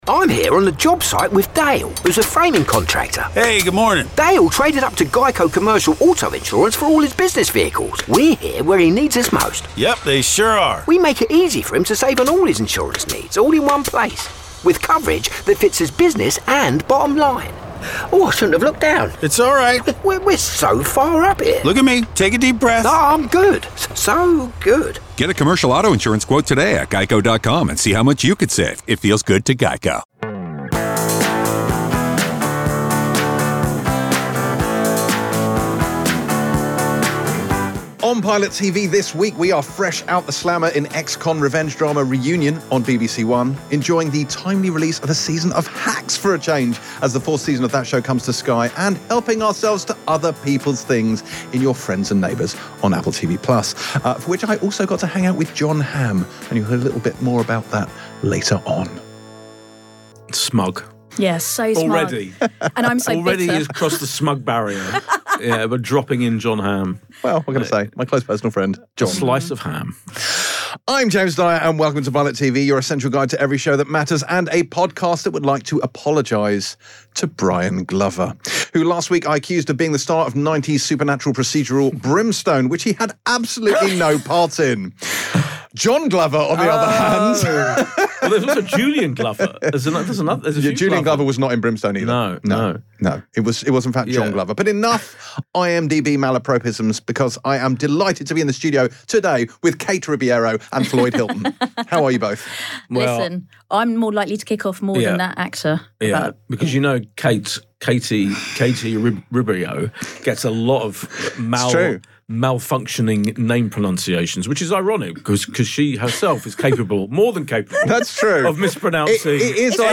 Click to listen, free! 459 episodes in the TV Reviews genre.
Your Friends & Neighbours, Reunion, and Hacks. With guest Jon Hamm